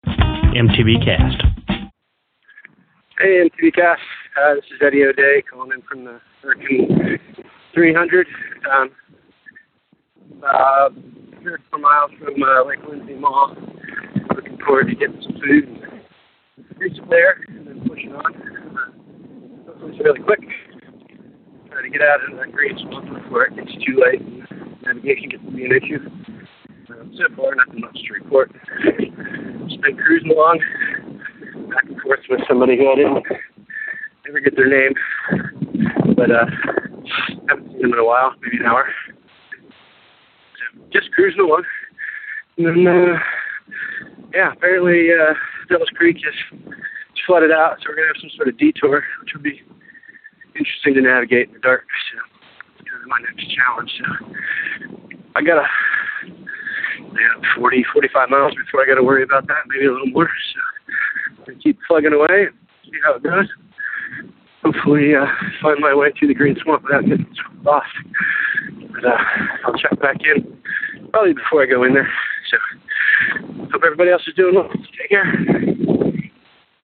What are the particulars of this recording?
called in as he closed in on Lake Lindsay Mall!